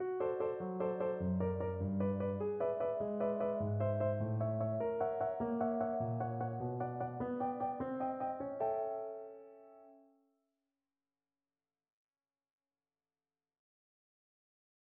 В тактах 33-48 оба фрагмента объединяются, и разработка проходит через модулирующее последование, затрагивающее ряд тональностей: